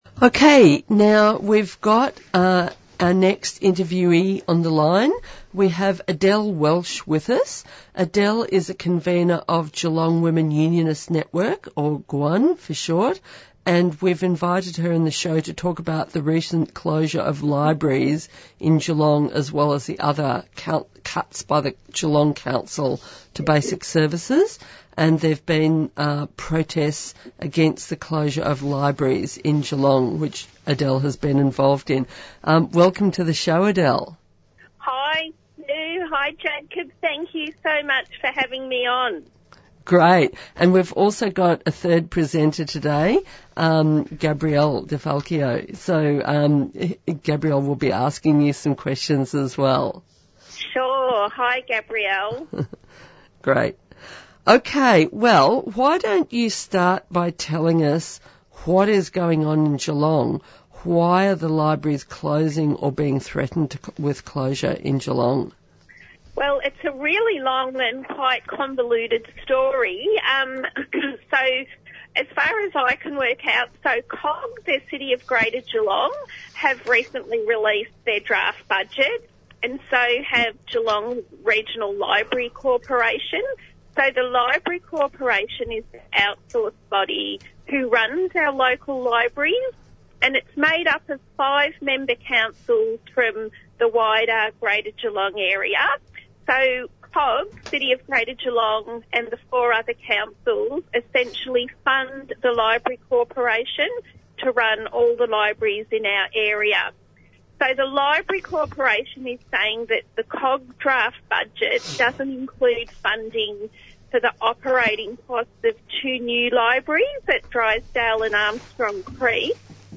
NewsreportsPresenters respond to the headline news story that Global heat will hit new records in next five years and how it raises the urgency of the Climate Crisis.
Interviews and Discussion Recording of a interview by John Tamihere from Te Pāti Māori (Maori Party) about the AUKUS military pact between Australia, the United States and Britain.